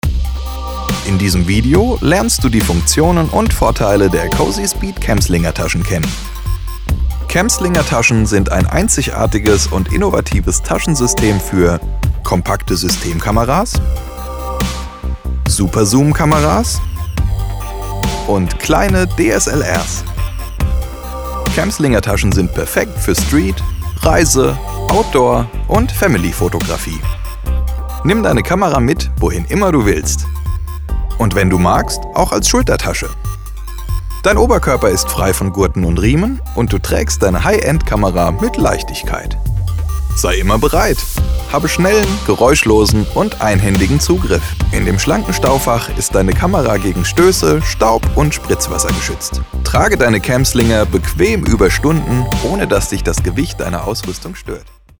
Stimme für Werbung & VoiceOver mit eigenem Studio
Kein Dialekt
Sprechprobe: Industrie (Muttersprache):